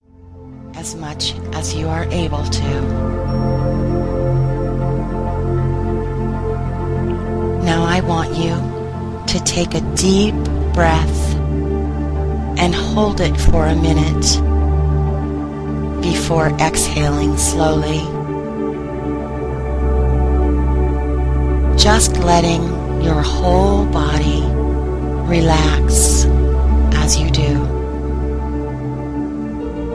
Hypnosis for social success in relationships and branding